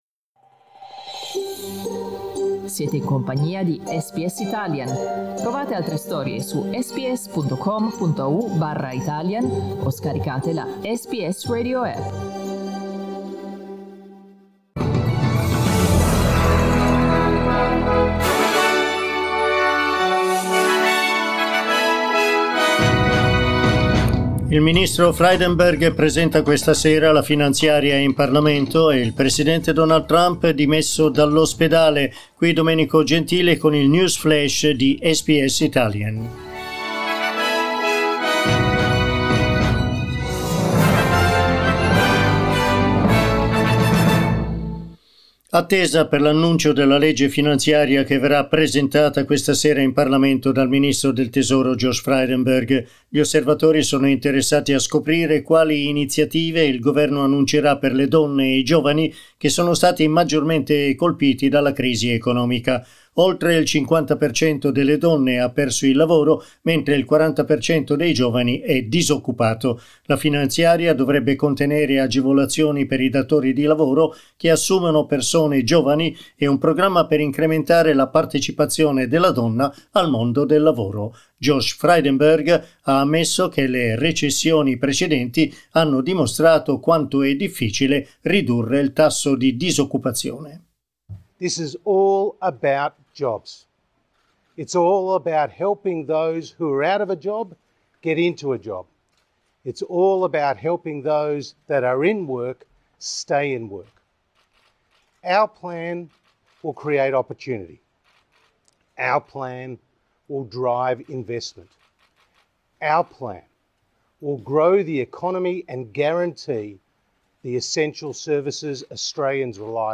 Aggiornamento del GR di SBS Italian.